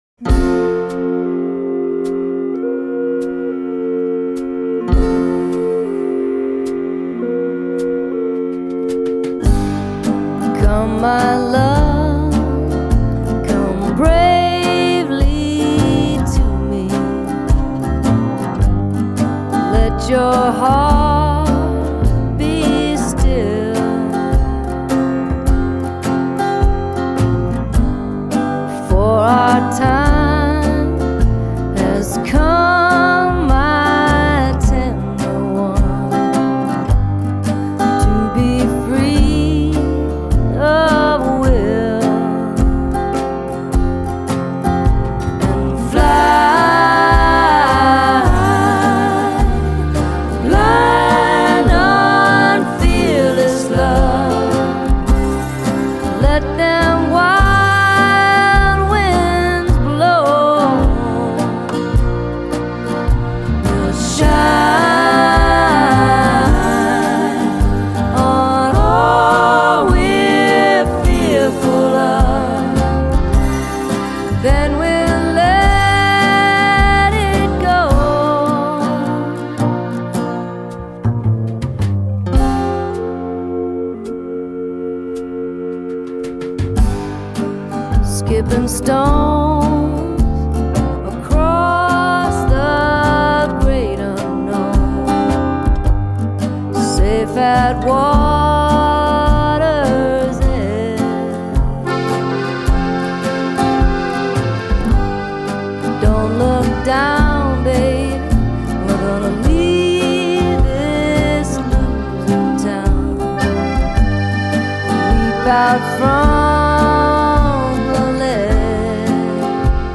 The quietly affecting melody, the unusual chorus harmonies